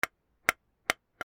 煙管(きせる)をトントン 1
/ M｜他分類 / L01 ｜小道具